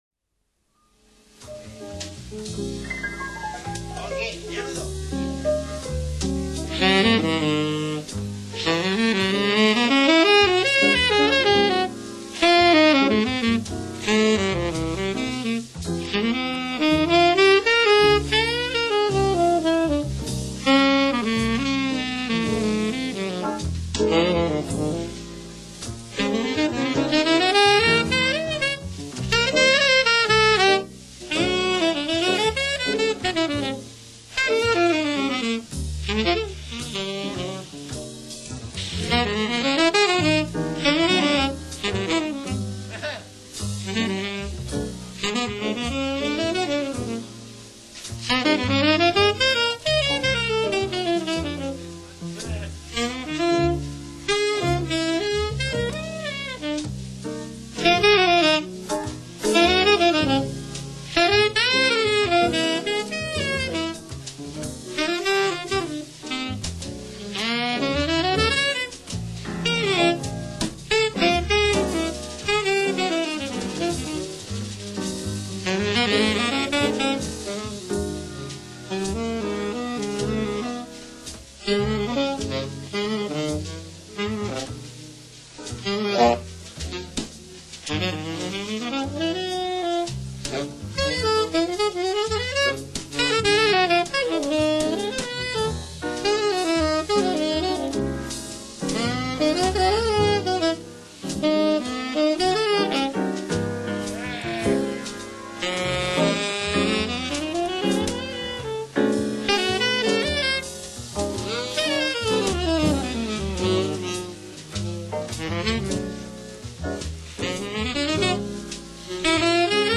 recorded at Karlsruhe, West Germany
Mellow
piano
Bass
Drums